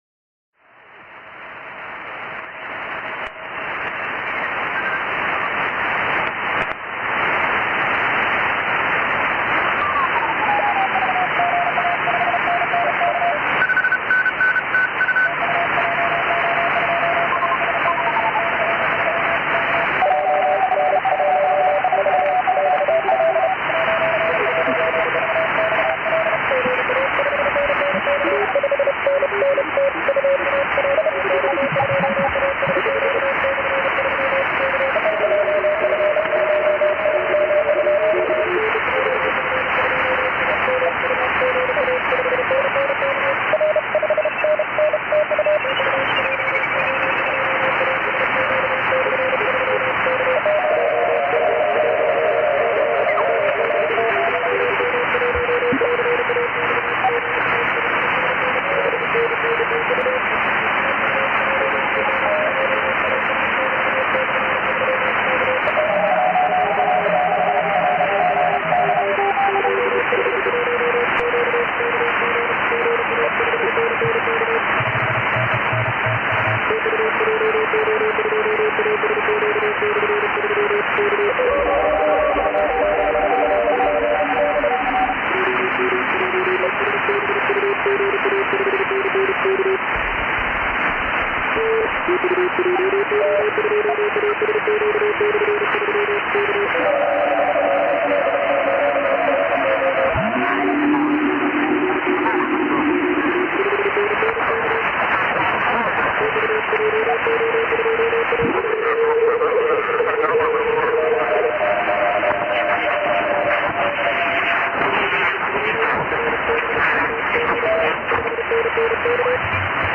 だって、怒パイル取るだけでも大変なのに、アンテナはときどき回さなけりゃいけないし、周波数はずっこけて逝ってしまうし、ずれて呼ぶ人いるし、「返り」を探す「お邪魔虫」多しだし、フェージングも深いし。
衛星は　FO-29, XW-2F, XW-2C の順ですが、全体で７~８分の長さに短縮しています。